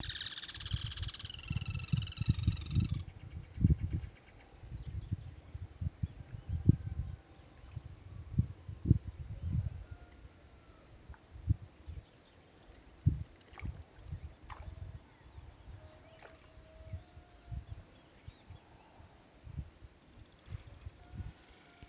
Certhiaxis cinnamomeus
Yellow-chinned Spinetail
[ "song" ]